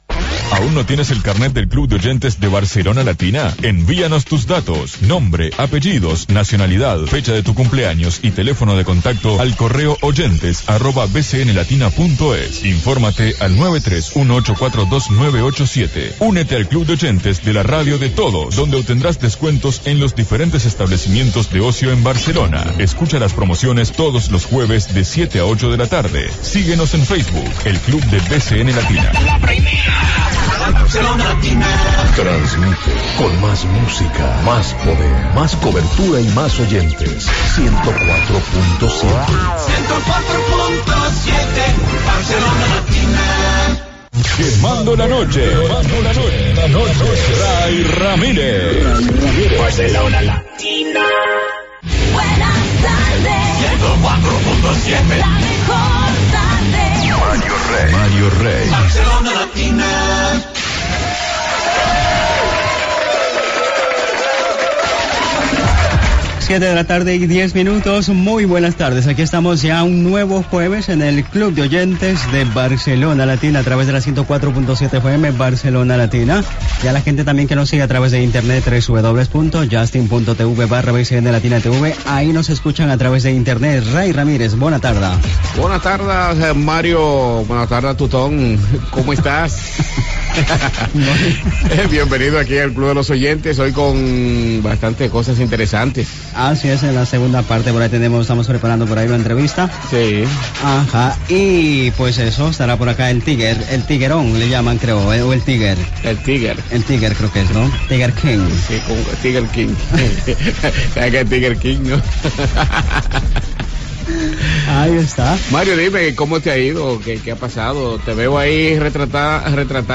Promoció del programa, indicatiu de la ràdio, presentació del programa, sumari, telèfon de participació, sorteig, dues trucades telefòniques